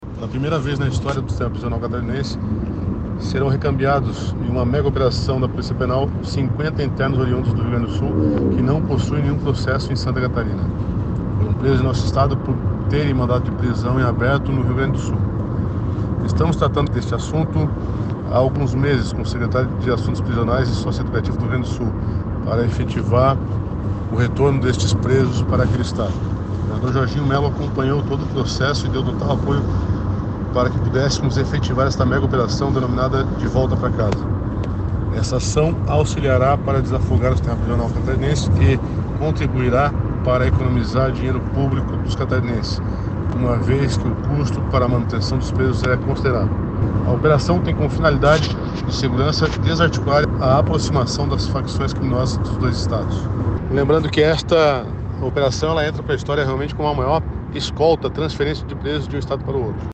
Ele comentou sobre a operação considerada como a maior escolta de transferência de presos de um estado para outro: